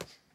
boxing_hit.wav